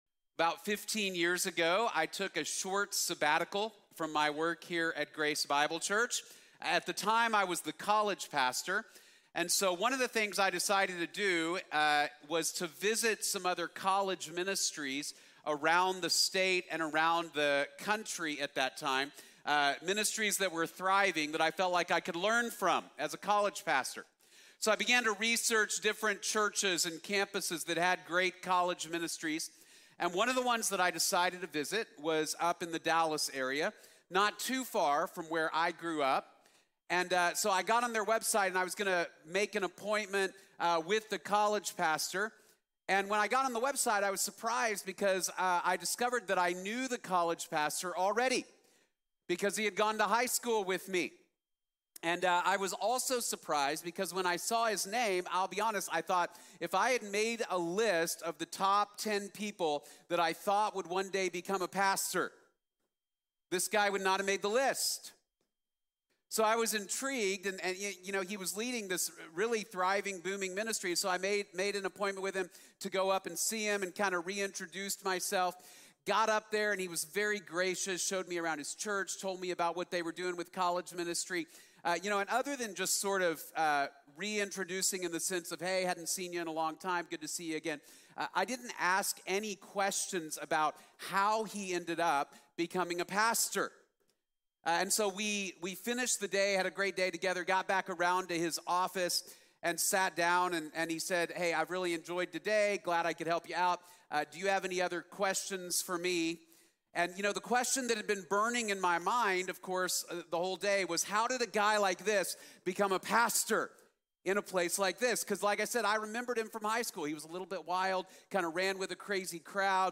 Lost and Found | Sermon | Grace Bible Church